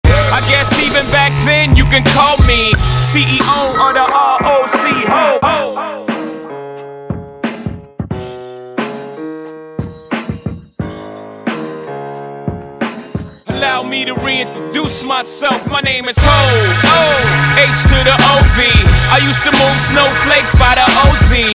Piano Riff
this track opens with a bold, soulful piano loop
BPM: 178